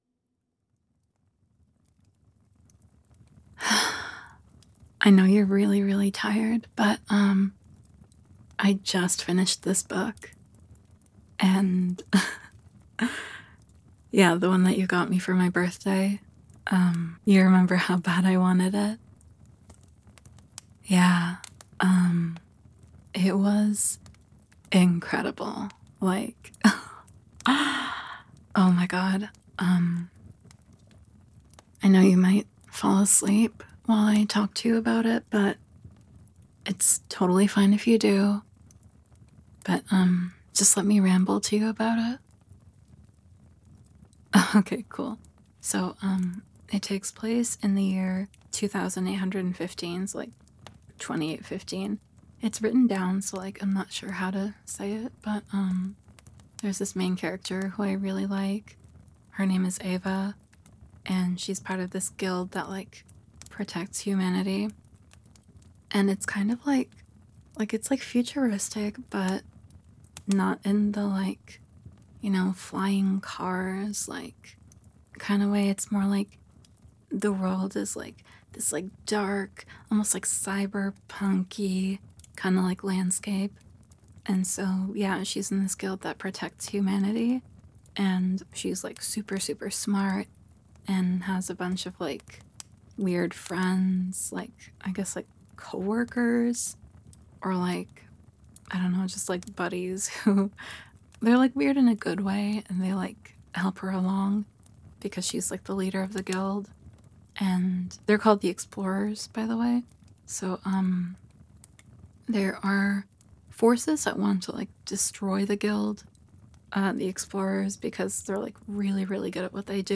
Tags: [SFW] [GFE] [Relaxing] [Crackling Fireplace] [In Bed] [Nerdy Girl] [Quiet, Hushed Ranting] [Talking To You Until You Fall Asleep] [Boring You] With An [Improvised Story] [Goodnight Forehead Kiss]